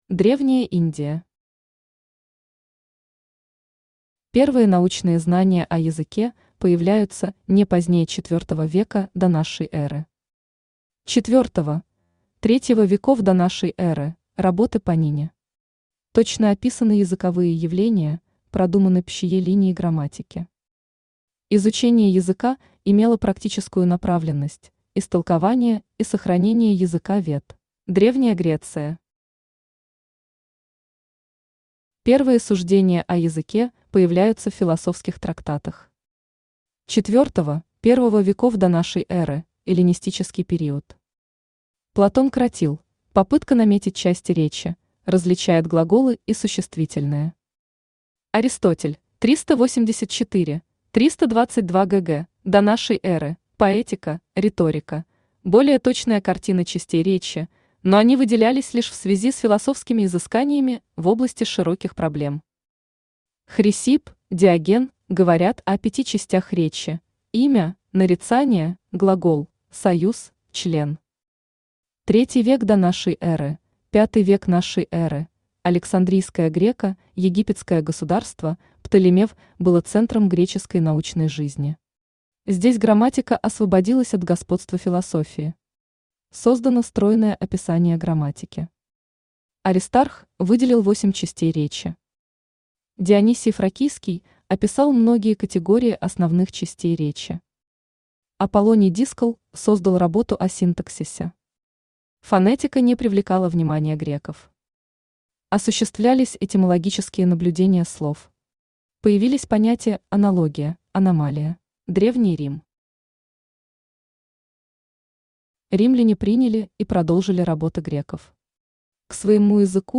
Аудиокнига Элементарно об истории языкознания. Конспективное изложение | Библиотека аудиокниг
Читает аудиокнигу Авточтец ЛитРес.